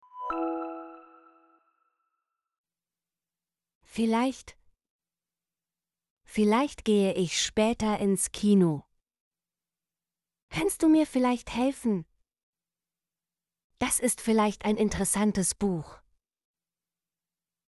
vielleicht - Example Sentences & Pronunciation, German Frequency List